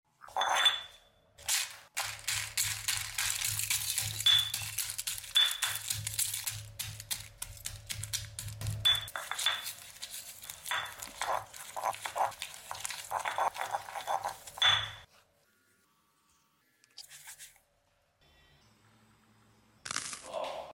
Upload By ASMR videos
Oddlysatisfying crushing golden coins into